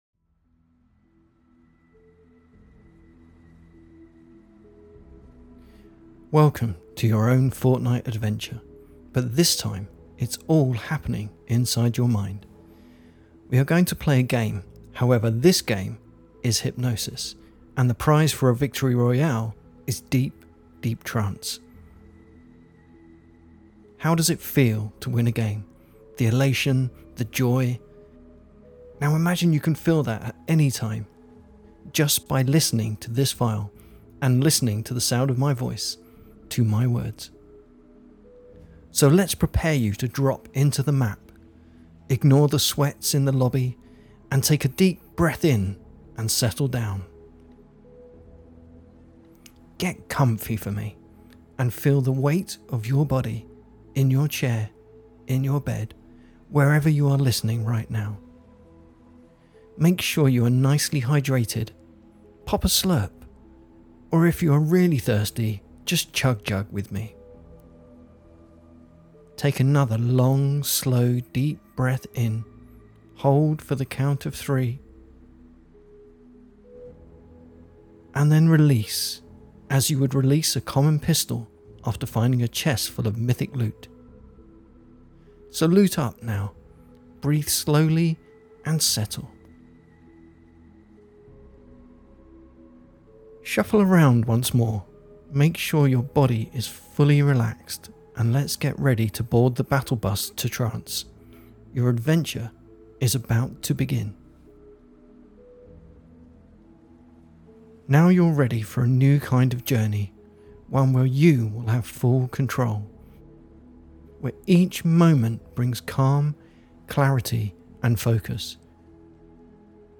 — Just a silly idea of using Fortnite terms in a hypno induction.